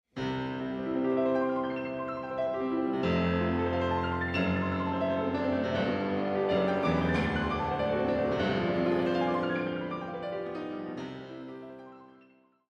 Many famous pieces feature arpeggios prominently in their design:
Chopin-Etude-Arps-Audio-.mp3